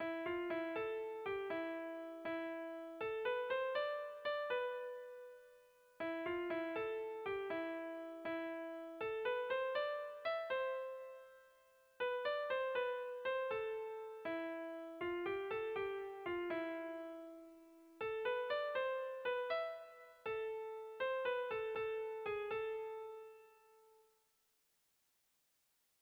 Erlijiozkoa
Joku polita egiten du bien artekoak, hau tonu minorrean eta bestea tonu maiorrean.
Zortziko txikia (hg) / Lau puntuko txikia (ip)
A1-A2-B-C